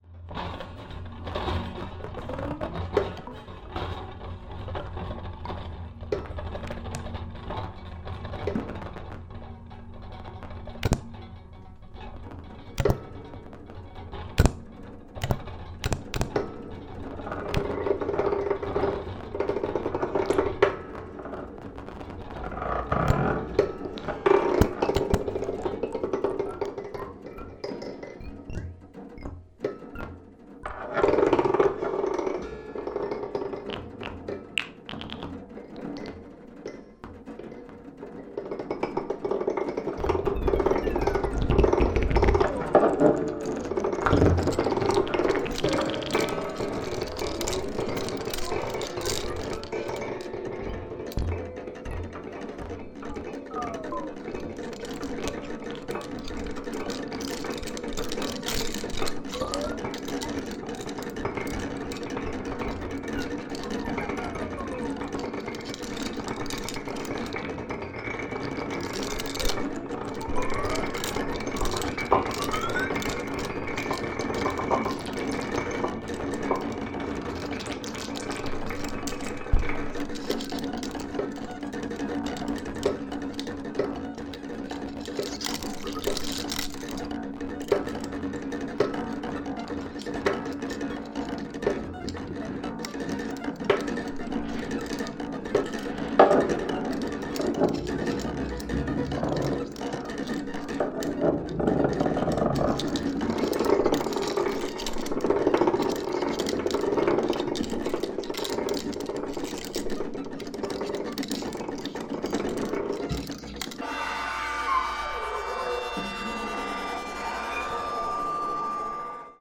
演奏者それぞれがクラヴィコードを使った凄まじくシリアスな鉱物即興&ライブエレクトロニクス作
キリキリと恐ろしい摩擦音と無骨な打撃の集積はもはや物音的な領域。
free improvised music by
monochord, clavichord, melodica
clavichord, electronics
tenor and bass recorder